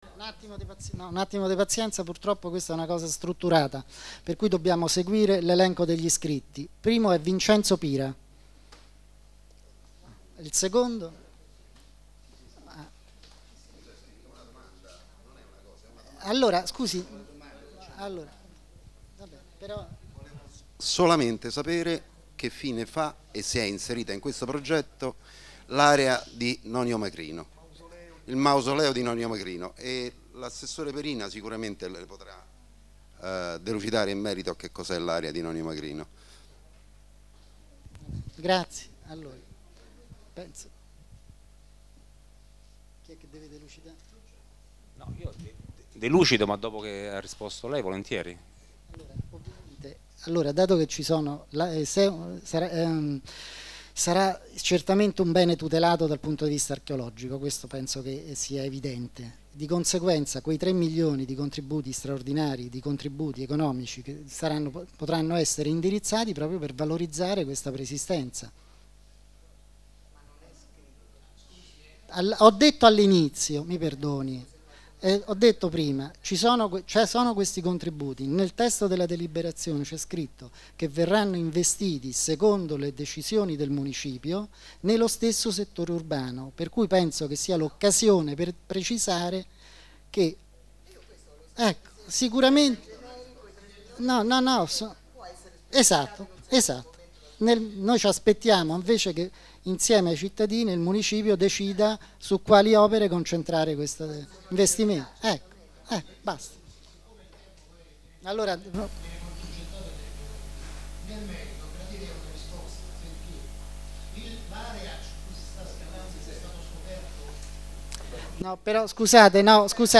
Registrazione integrale dell'incontro svoltosi il giorno 8 maggio 2012 presso
la sala consiliare del Municipio Roma 20